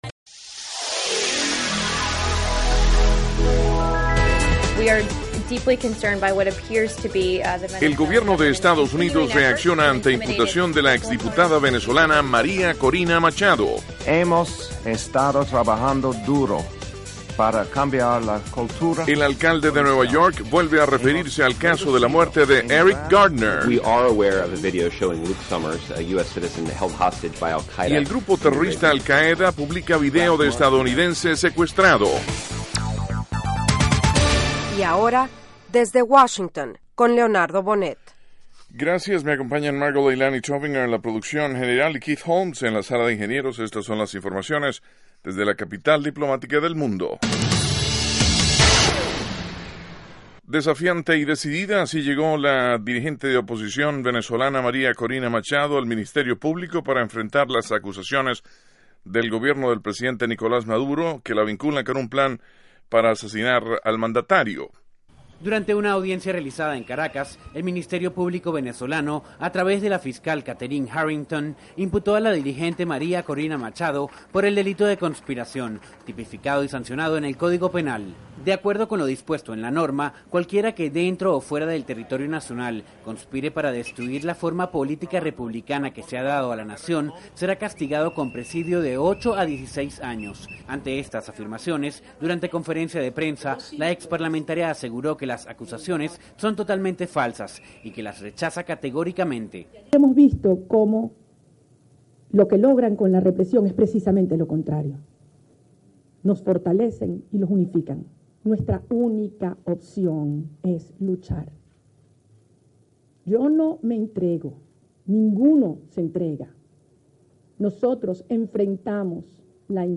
Con entrevistas a líderes políticos, nacionales y extranjeros, Desde Washington ofrece las últimas noticias sobre los acontecimientos que interesan a nuestra audiencia. El programa se transmite de lunes a viernes de 8:00 p.m. a 8:30 p.m. (hora de Washington).